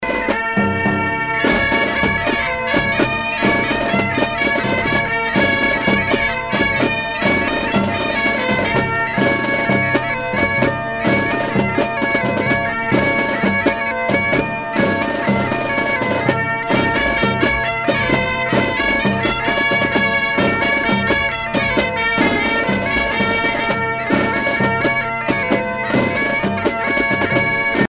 danse : gavotte bretonne